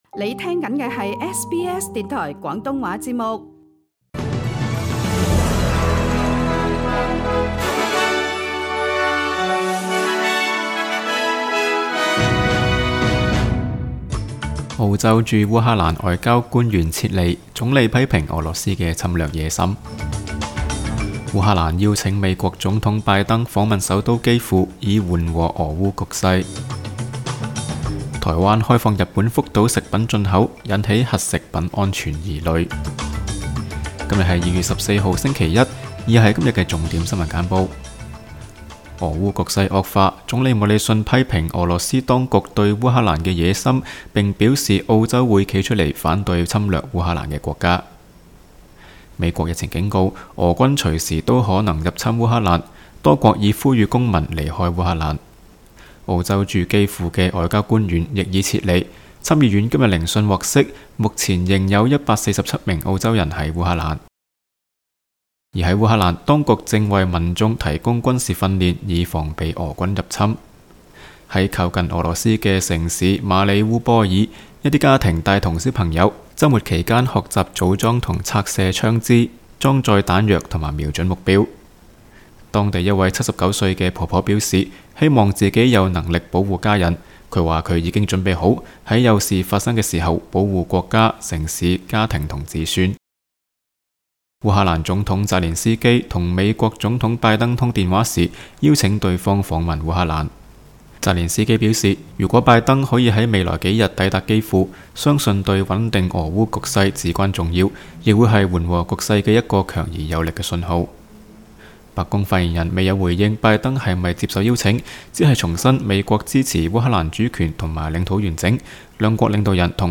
SBS 新闻简报（2月14日）
SBS 廣東話節目新聞簡報 Source: SBS Cantonese